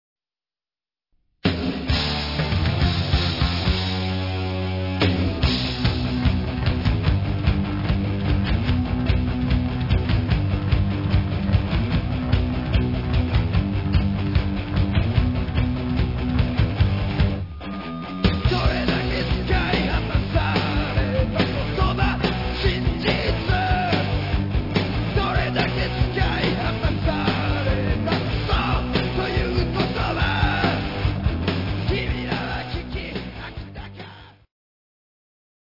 コメント 関西パンクのマスターピース！！